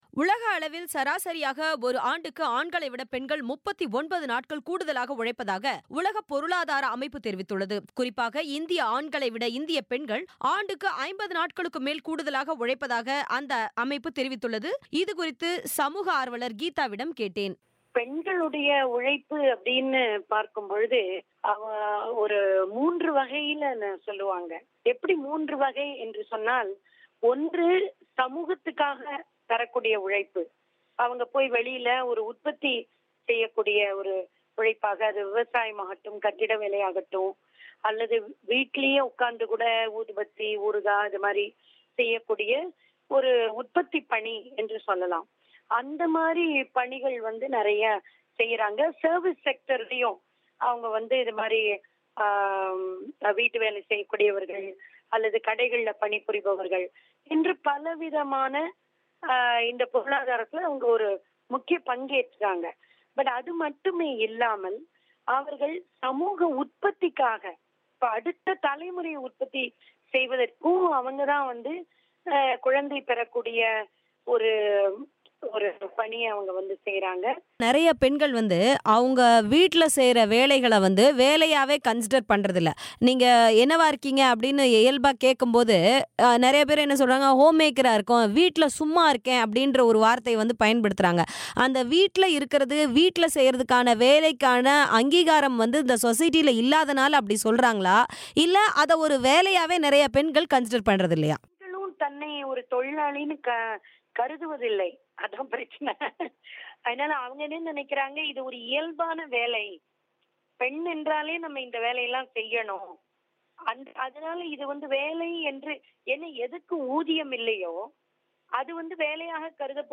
ஆண்களை விட பெண்கள் அதிகம் உழைக்கும் நிலை குறித்த பேட்டி